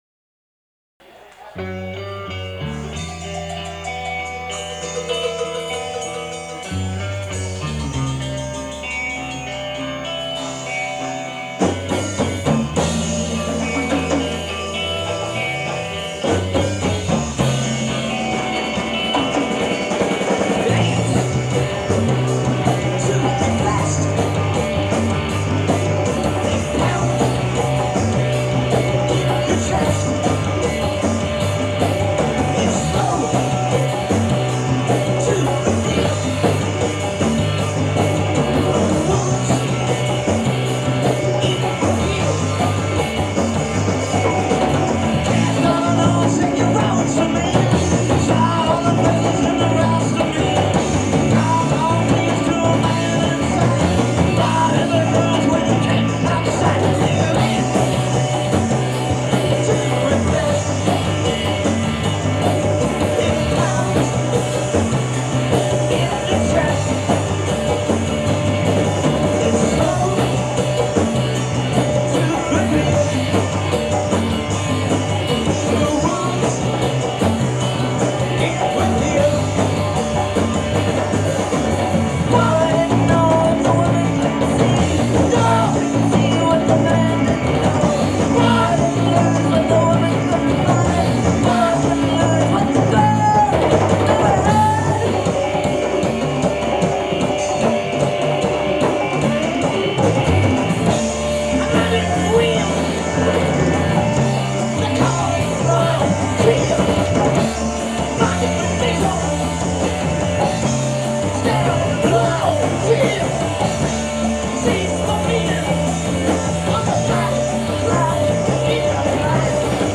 Live – Khyber Pass 10-22-90